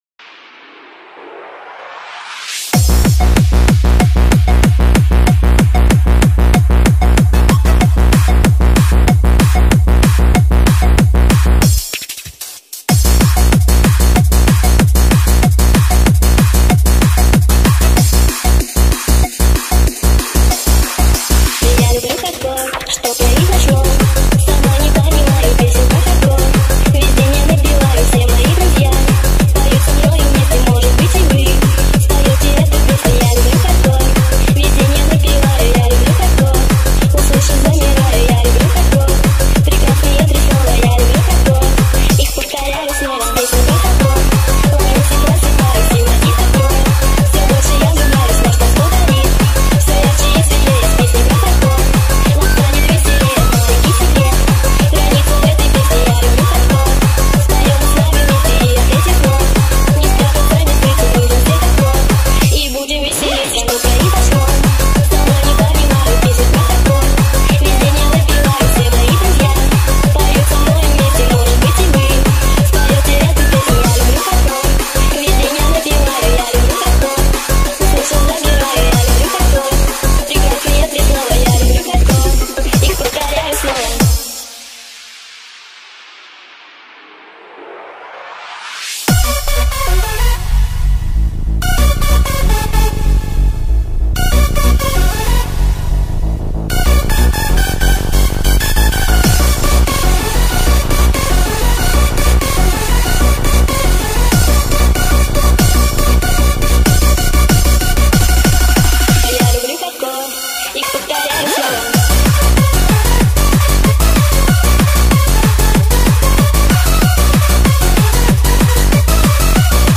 speed up remix
sped up remix